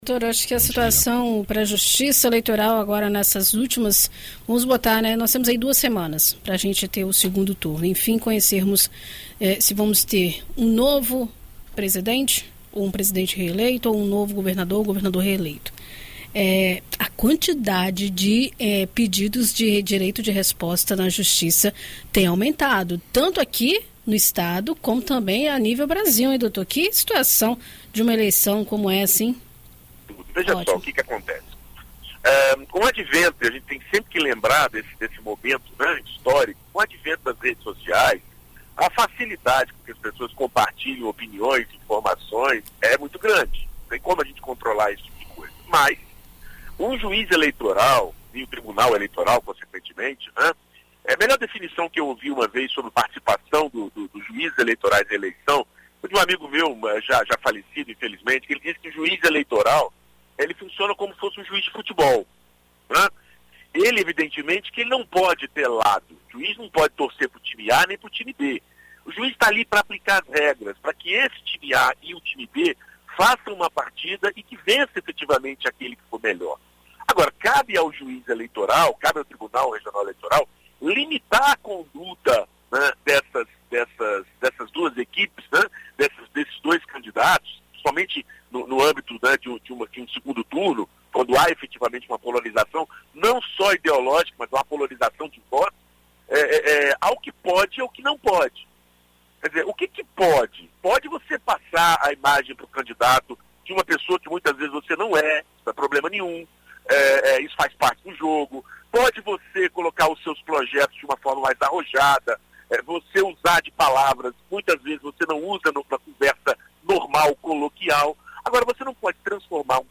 Na coluna Direito para Todos desta segunda-feira (17), na BandNews FM Espírito Santo